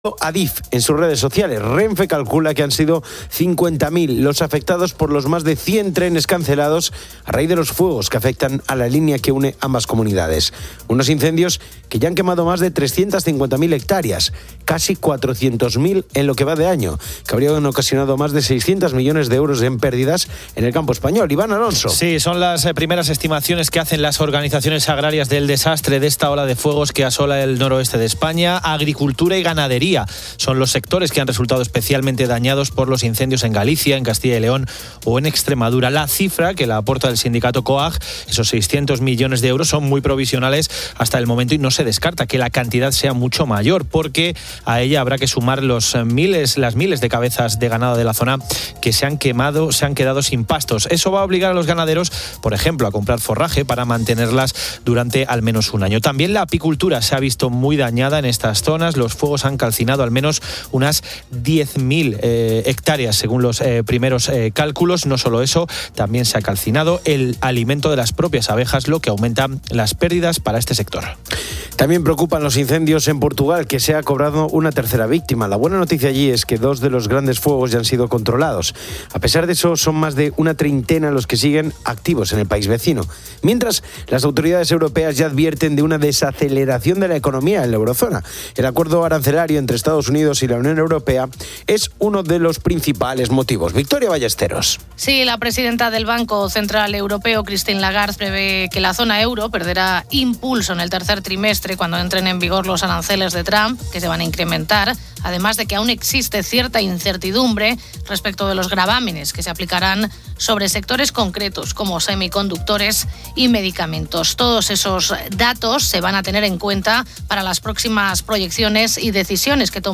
Resumen de las noticias y la entrevista: **Noticias:** * **Incendios:** Más de 100 trenes cancelados por incendios en España (50.000 afectados).